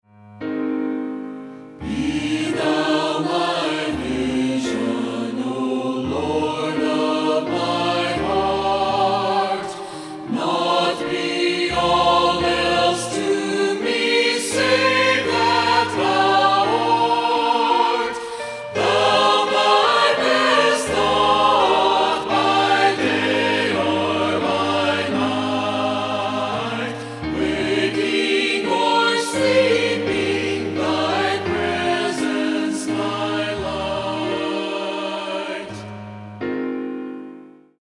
STUDIO: Broadway Recording Studio, Broken Bow, OK
CONGREGATIONAL ANTHEM DEMOS